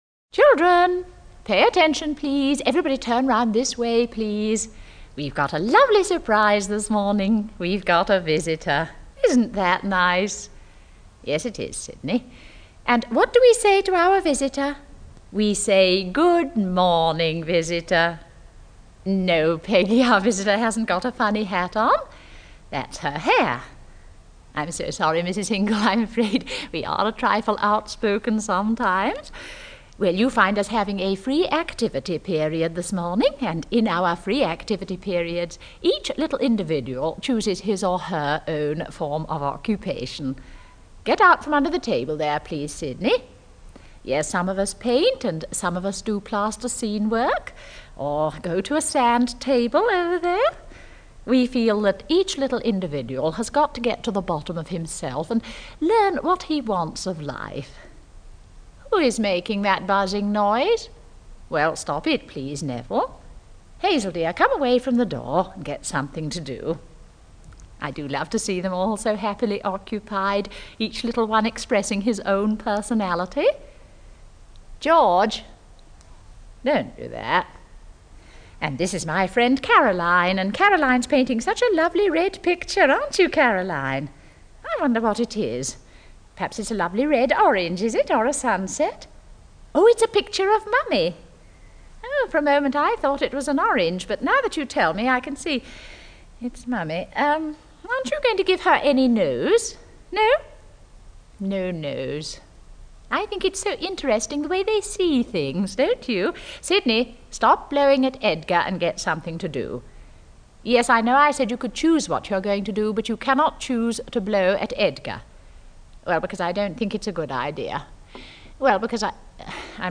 Joyce Grenfell's amusing monologue on the Nursery School The Calf Path by Sam Foss (poem) TP